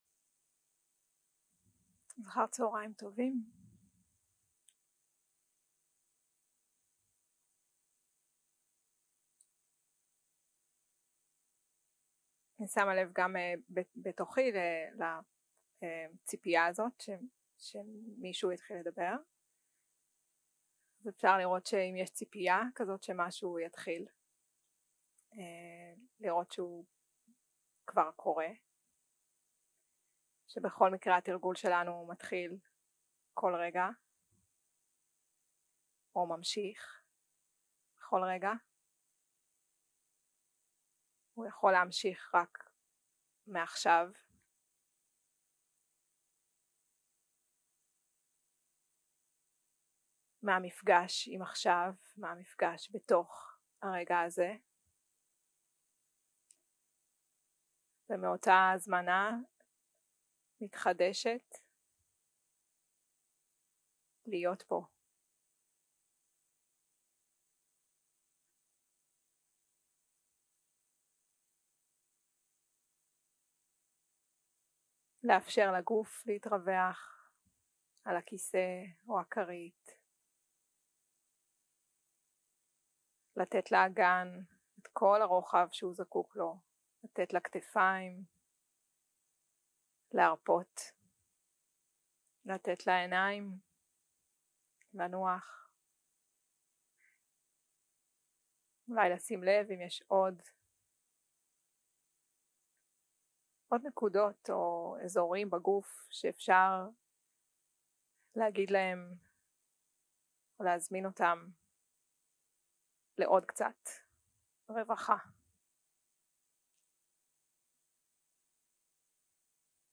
יום 2 - הקלטה 3 - צהרים - מדיטציה מונחית - קבלה ותשומת לב נדיבה
יום 2 - הקלטה 3 - צהרים - מדיטציה מונחית - קבלה ותשומת לב נדיבה 0:00 0:00 סוג ההקלטה: Dharma type: Guided meditation שפת ההקלטה: Dharma talk language: Hebrew